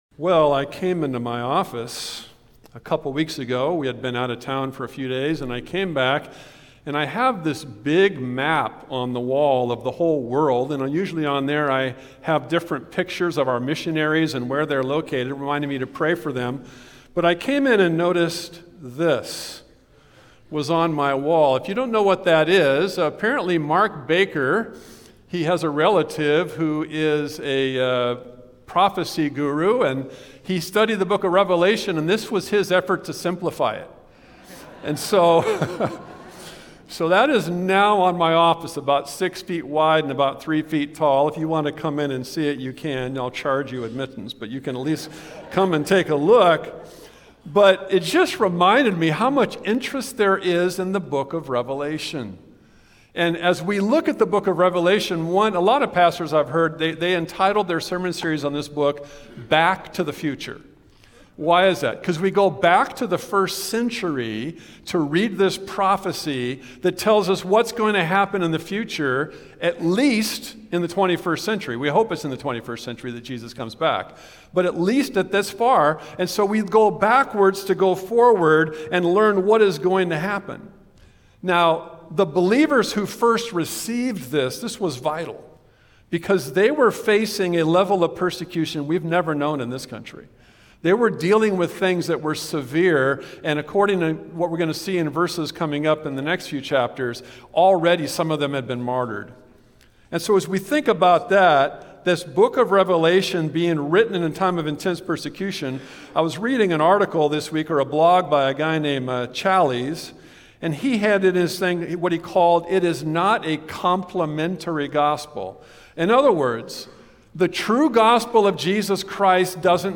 Service Type: Sunday Worship Service